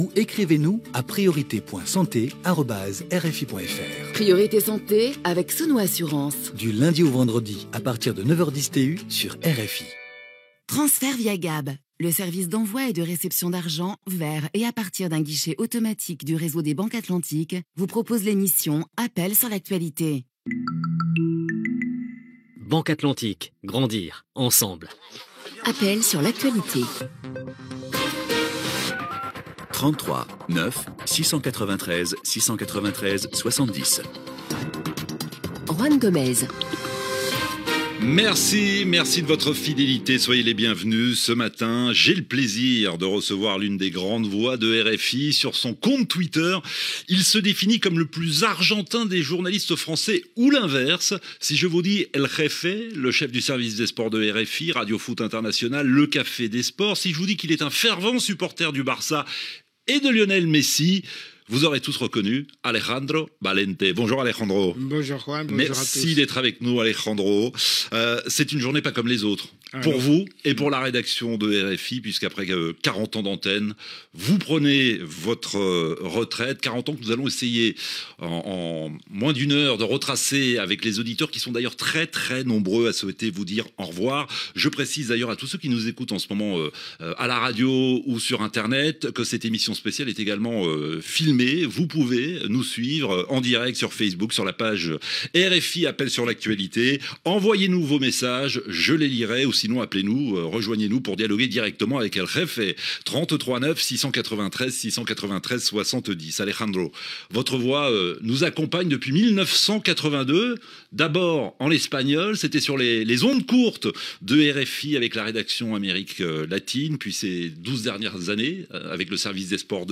FM